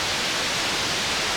static.ogg